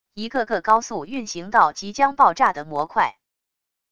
一个个高速运行到即将爆炸的模块wav音频